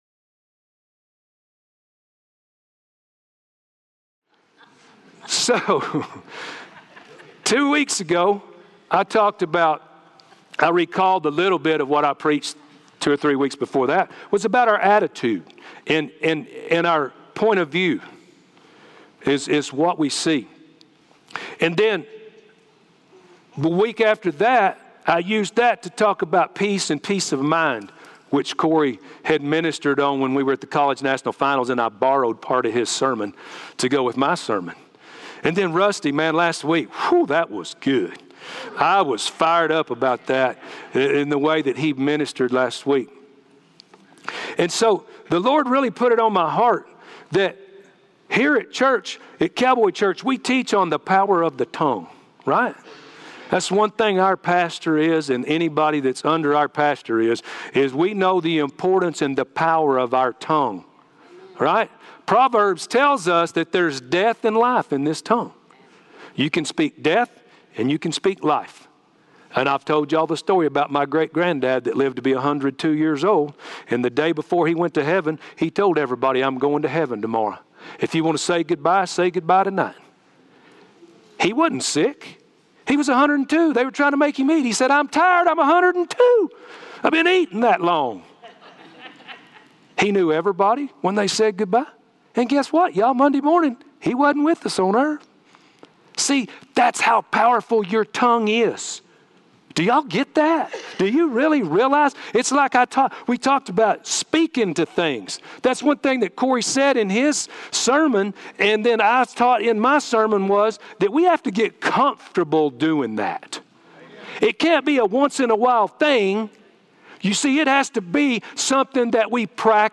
Live Stream Our Complete Service Sunday mornings at 10:30am Subscribe to our YouTube Channel to watch live stream or past Sermons Listen to audio versions of Sunday Sermons
Mar 7, 2021 | by Guest Preacher